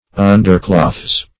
Underclothes \Un"der*clothes`\, n. pl.